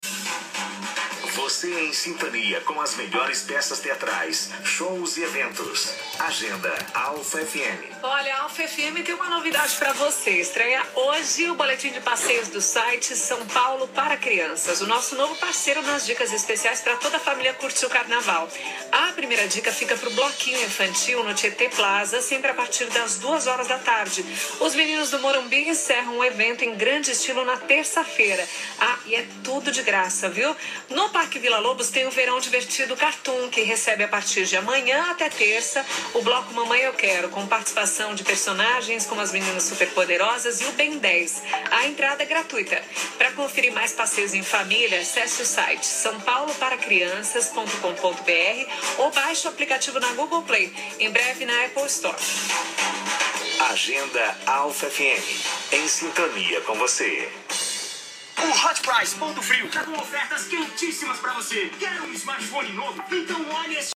Toda sexta-feira você pode acompanhar as melhores dicas de passeios em família no “Boletim São Paulo para Crianças”, na seção de Cultura da Alpha FM!